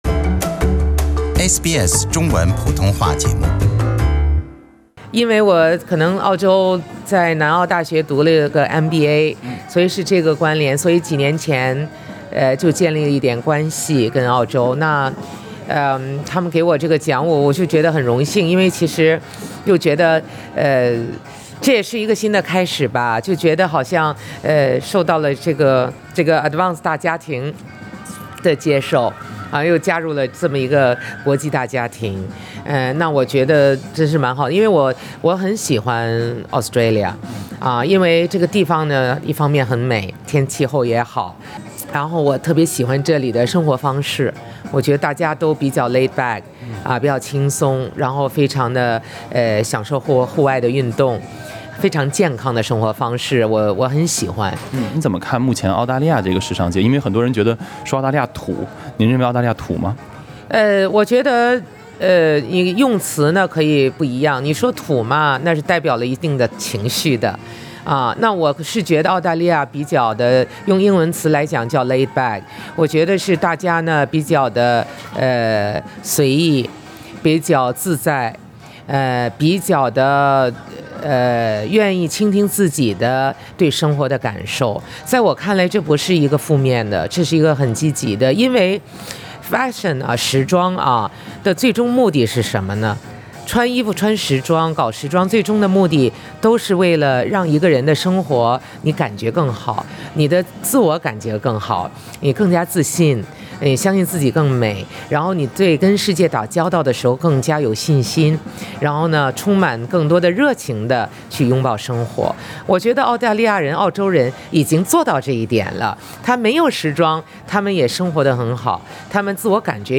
【人物】專訪Vogue中國主編張宇：認同澳洲“做自己”的文化